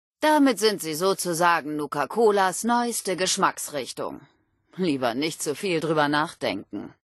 Wastelanders: Audiodialoge